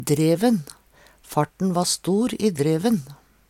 DIALEKTORD PÅ NORMERT NORSK dreven ovarenn i hoppbakke Eintal ubunde Eintal bunde Fleirtal ubunde Fleirtal bunde dreven Eksempel på bruk Farten va stor i dreven.